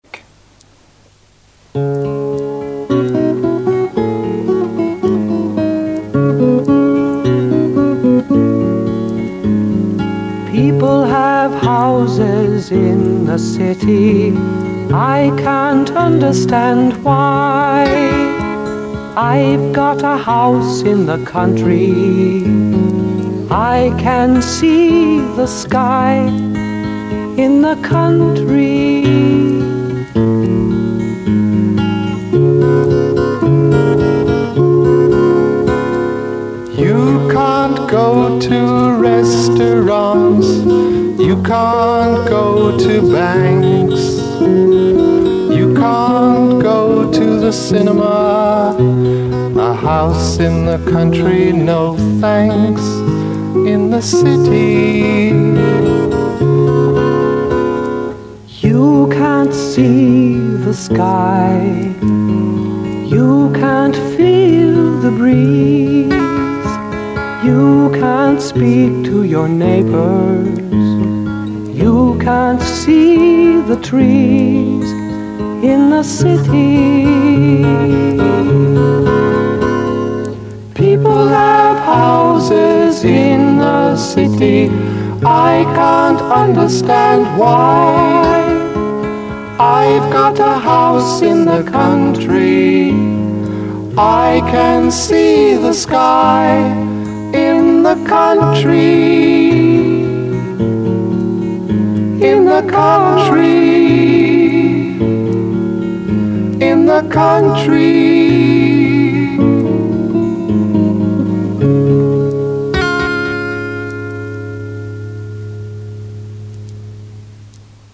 con esta canción no comercial